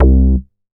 MoogRCA 009.WAV